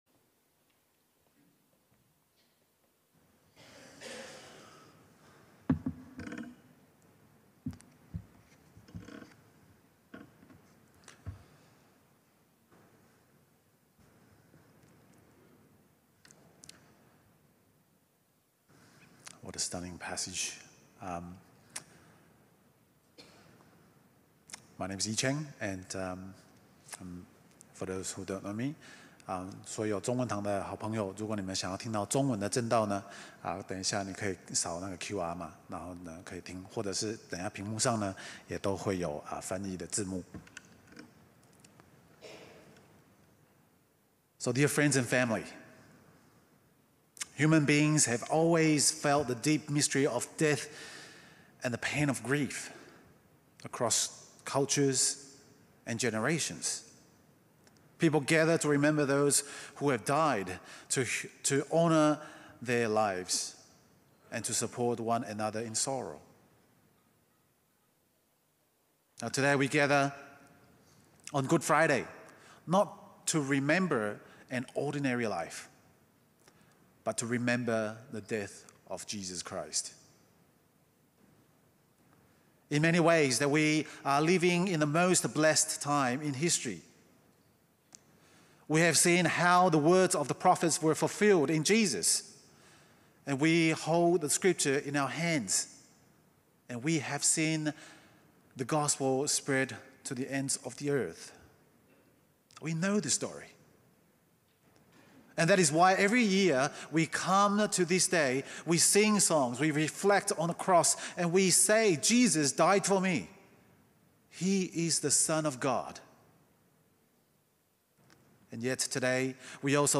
Mark 14:32 - 15:47 Sermon